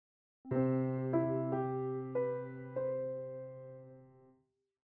Emocional